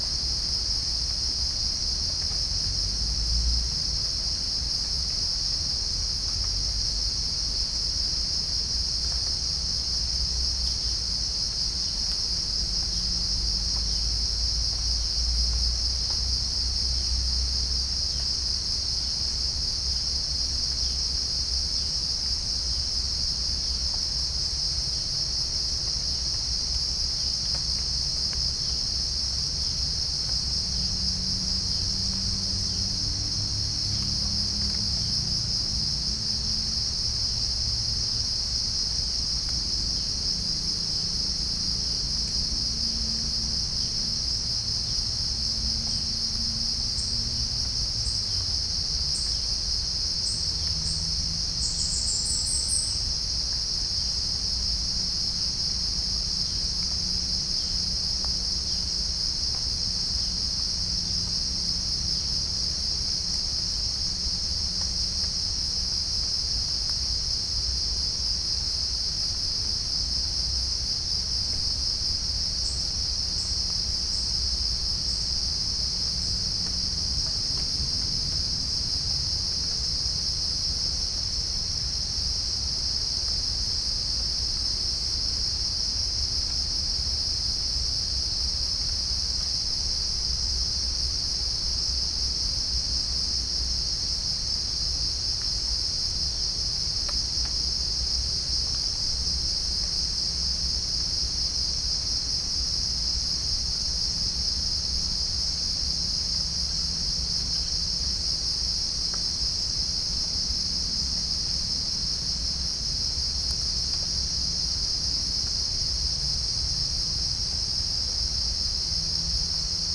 Chalcophaps indica
Pycnonotus goiavier
Pycnonotus aurigaster
Halcyon smyrnensis
Orthotomus ruficeps
Todiramphus chloris
Dicaeum trigonostigma